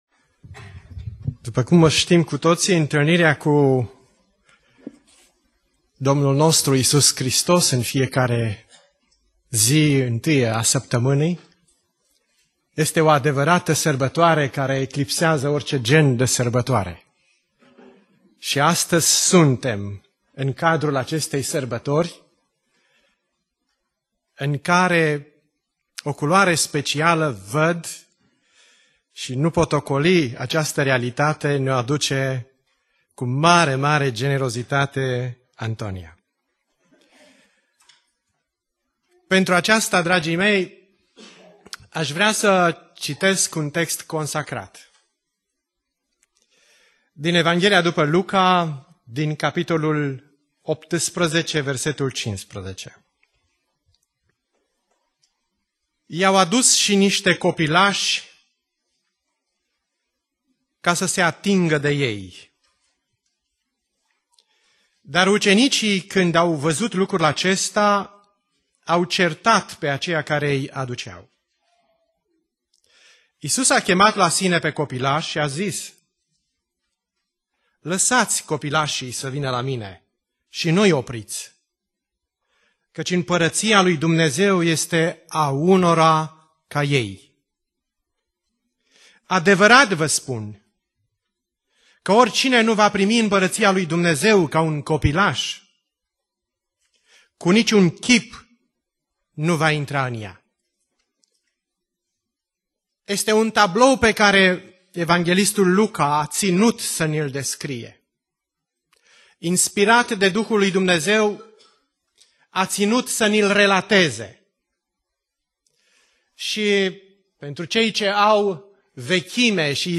Predica Aplicatie - Isaia 58-59